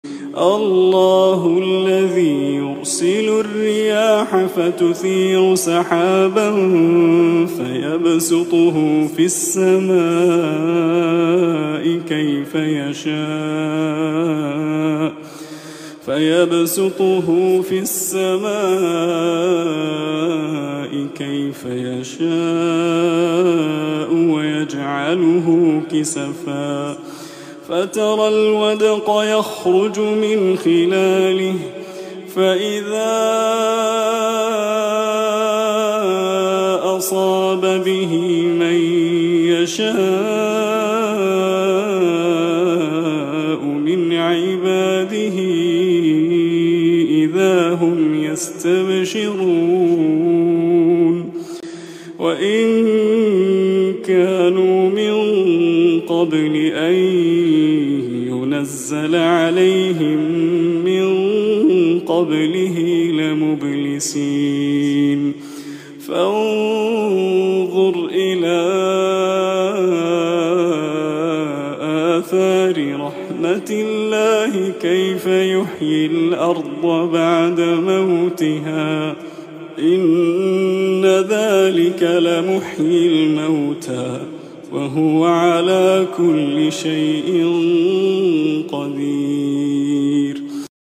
تلاوة خاشعة متقنة من سورة الروم
جامع : الأميرة هيا بنت تركي ، جدة